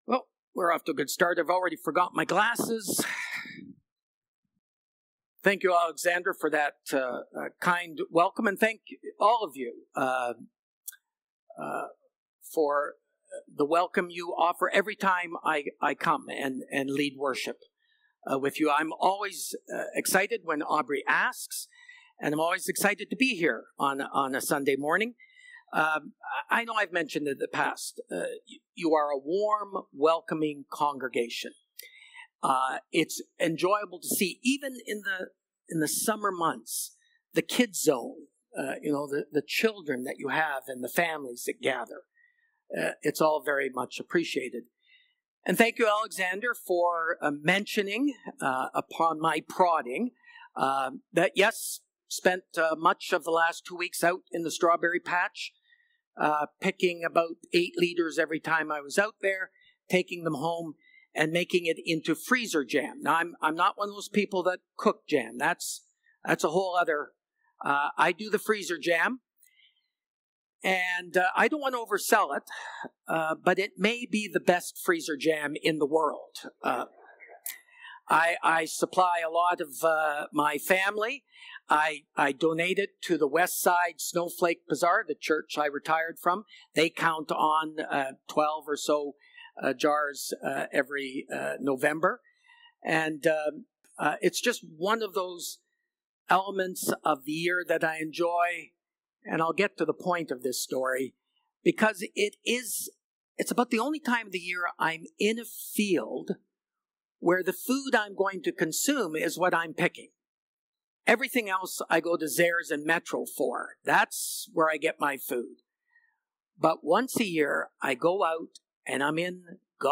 July-6-Sermon.mp3